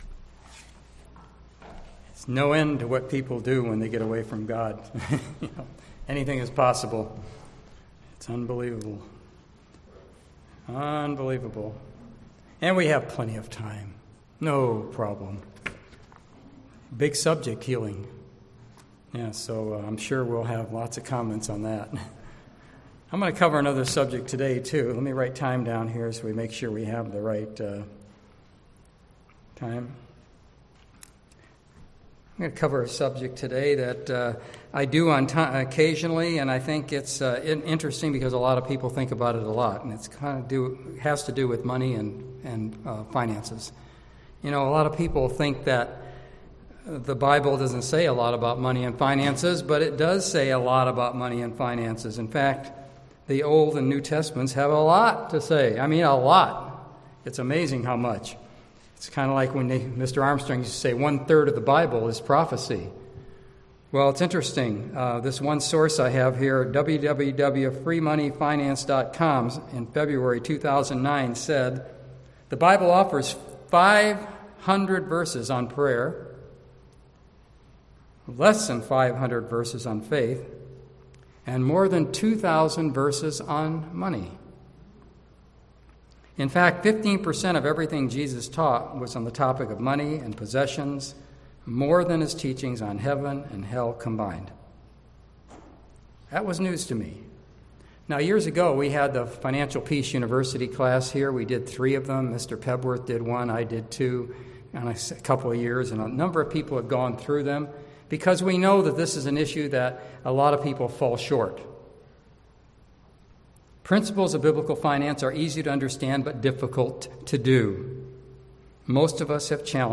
Listen to this sermon to learn how to be a good steward of financial blessings God has given you.